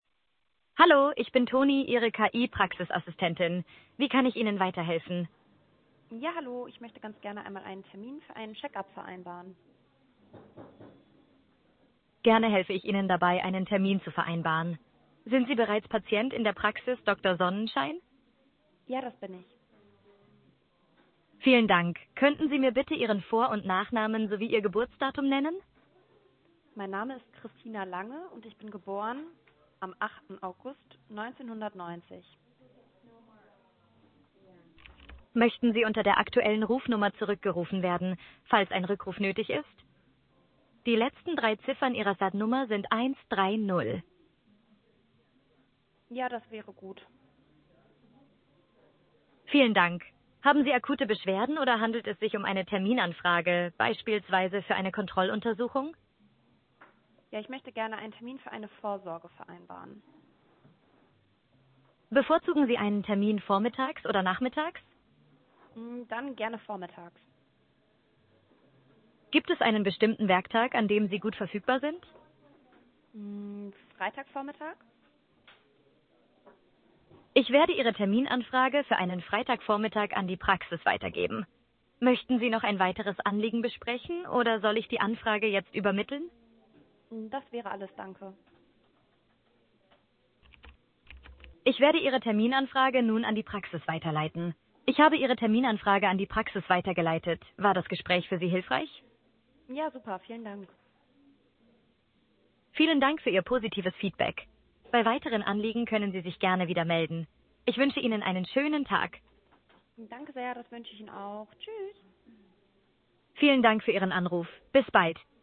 praxisToni spricht mit einer menschlich klingenden Stimme, auf Basis der aktuellste
Testanruf-Terminvereinbarung.mp3